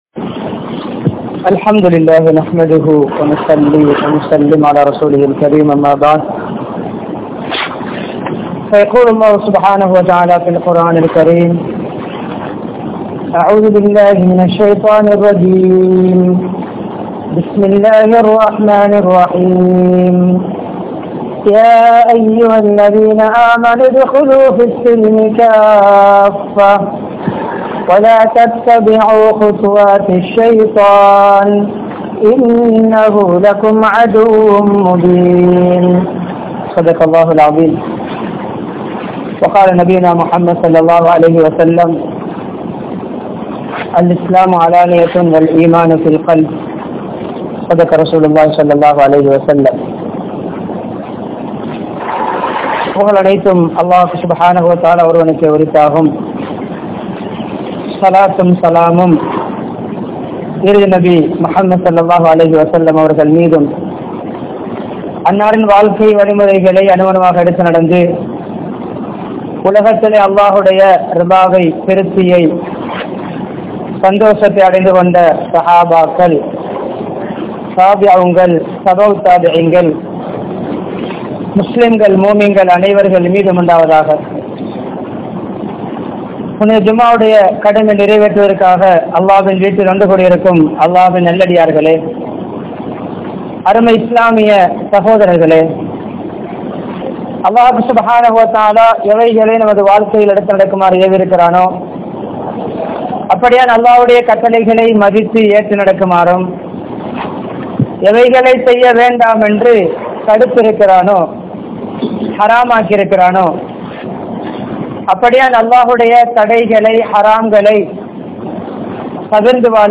Unmaiyaana Muslimin Adaiyaalangal (உண்மையான முஸ்லிமின் அடையாளங்கள்) | Audio Bayans | All Ceylon Muslim Youth Community | Addalaichenai
Wellampittiya, Sedhawatte, Ar Rahmath Jumua Masjidh